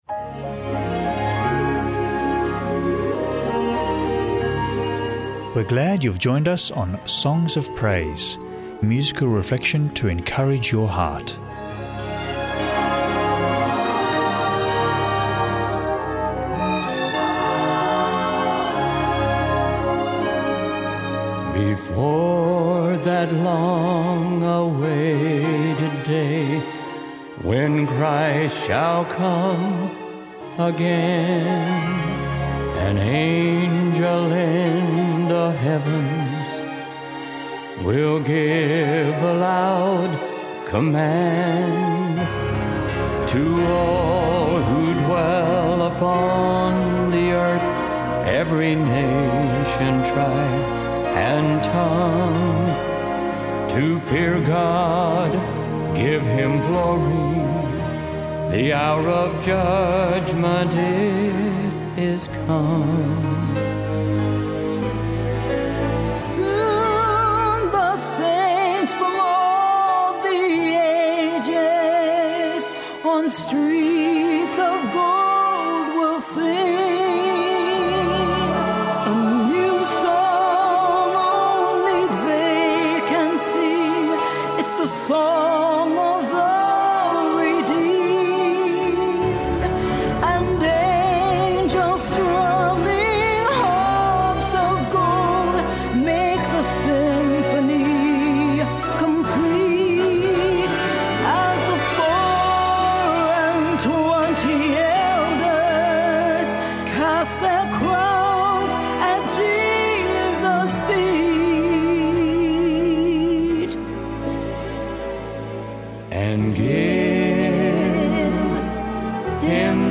Enjoy episodes of musical reflection to encourage, uplift and draw you into a closer relationship with our loving Saviour, Jesus Christ. Also featuring a short 3ABN Australia Radio Book Reading.